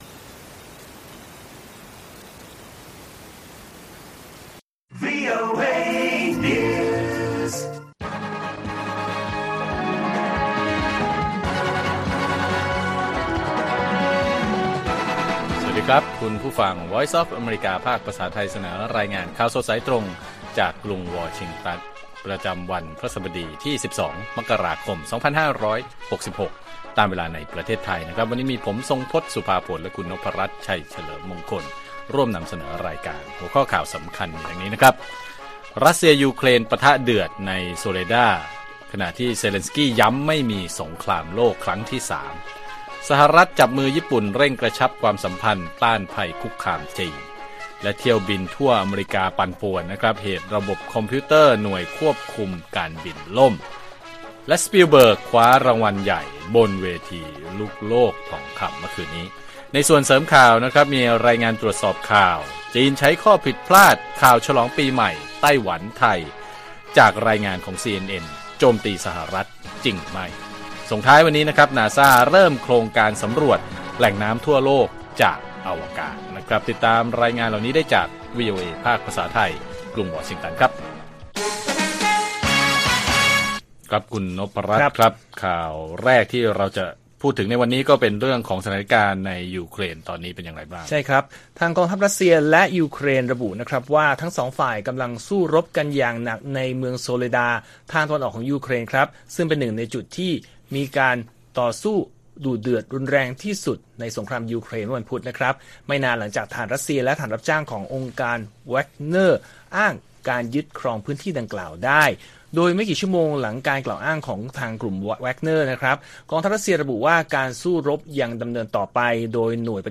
ข่าวสดสายตรงจากวีโอเอไทย พฤหัสบดี ที่ 12 ม.ค. 66